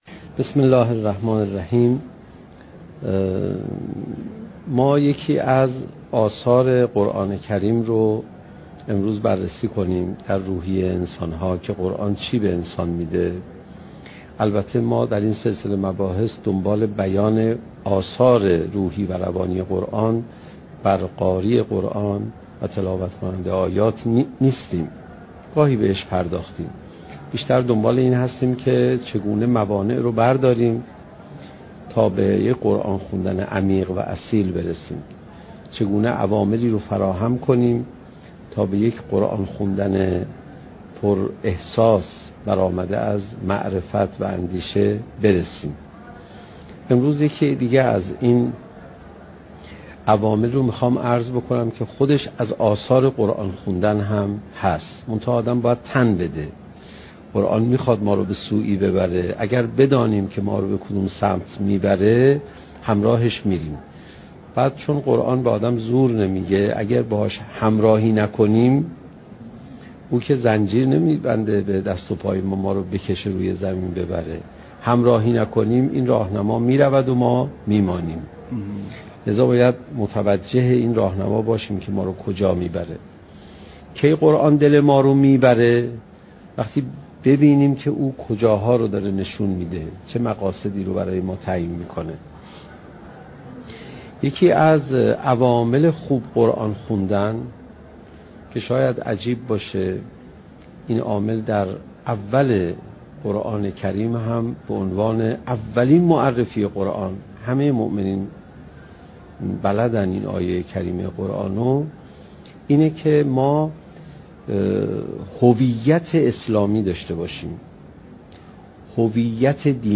سخنرانی حجت الاسلام علیرضا پناهیان با موضوع "چگونه بهتر قرآن بخوانیم؟"؛ جلسه بیست و چهارم: "هویت بخشی قرآن"